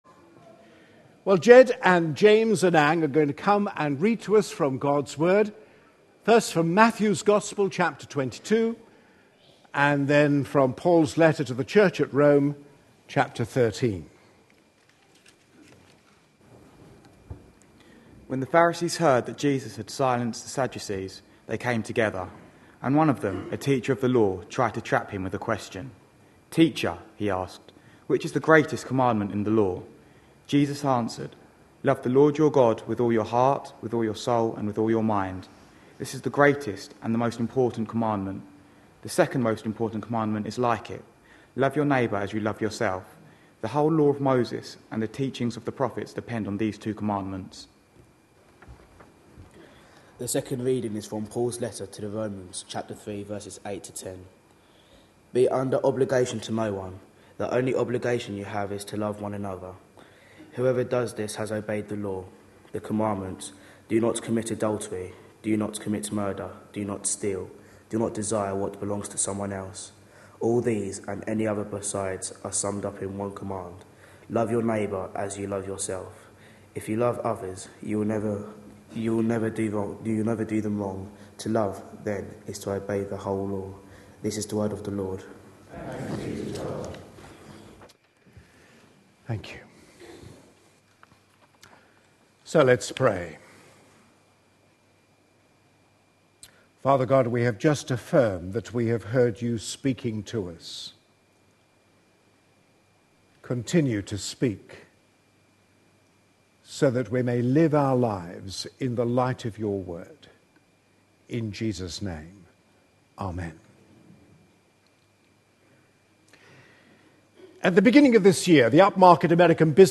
A sermon preached on 8th July, 2012, as part of our Red Letter Words series.